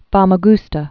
(fämə-gstə, fămə-)